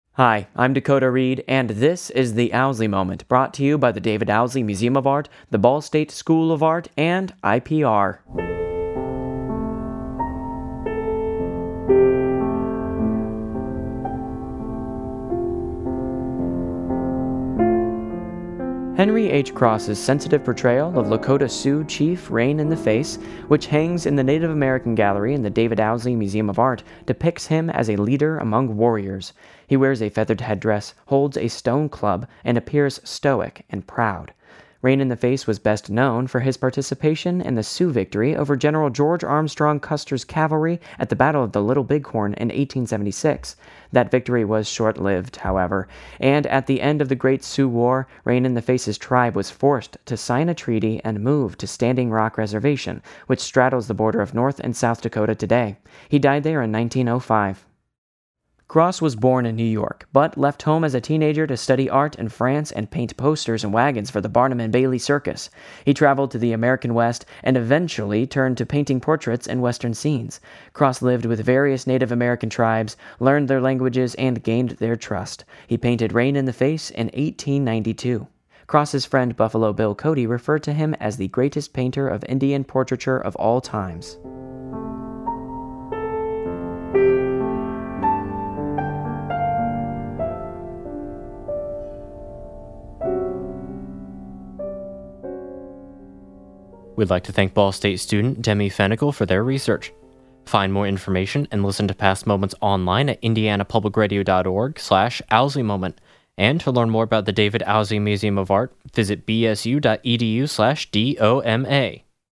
Music used in this episode:
They are produced in collaboration with the David Owsley Museum of Art and Indiana Public Radio and are voiced by Ball State students.